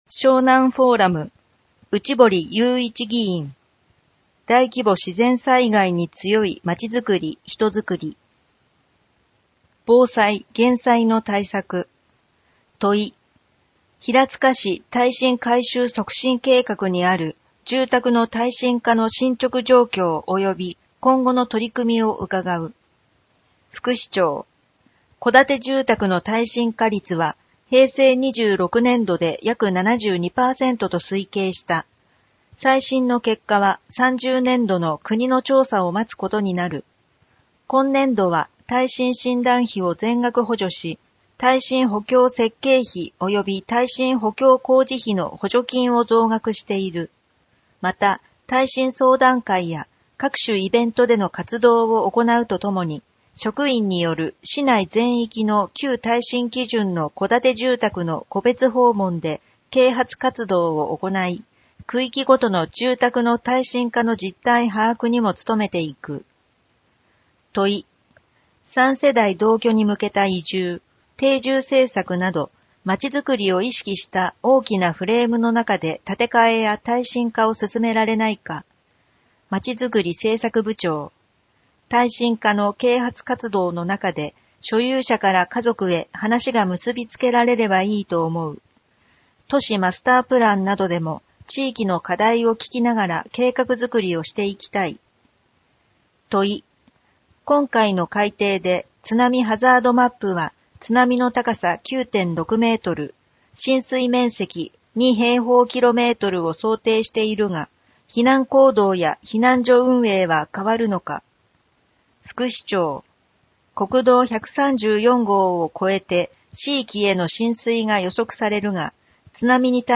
平塚市議会では目の不自由な方に、ひらつか議会だよりを音声化した「声の議会だより」と、掲載記事を抜粋した「点字版議会だより」をご用意しています。
「声の議会だより」は平塚市社会福祉協議会と平塚音訳赤十字奉仕団の協力により作成しています。